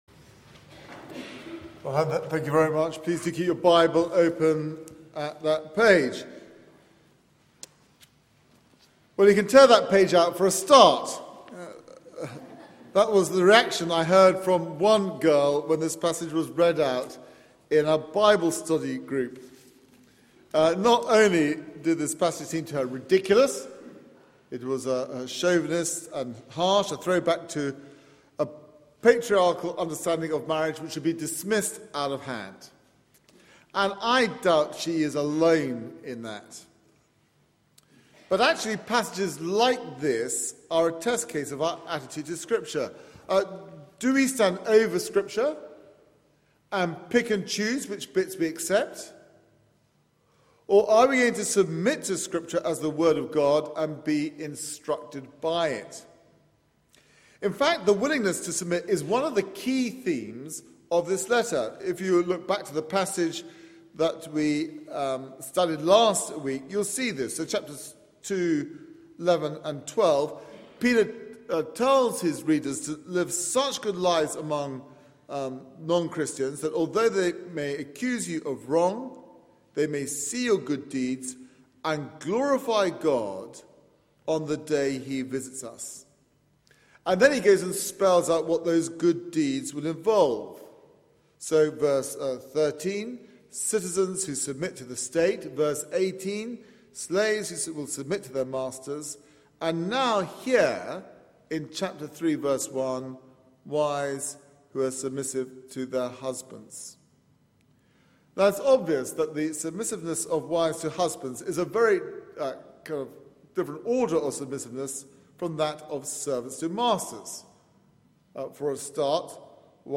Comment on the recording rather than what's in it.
Media for 9:15am Service on Sun 02nd Mar 2014 09:15 Speaker